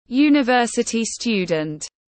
Sinh viên đại học tiếng anh gọi là university student, phiên âm tiếng anh đọc là /ˌjuː.nɪˈvɜː.sə.ti ˈstjuː.dənt/.
University student /ˌjuː.nɪˈvɜː.sə.ti ˈstjuː.dənt/
University-student.mp3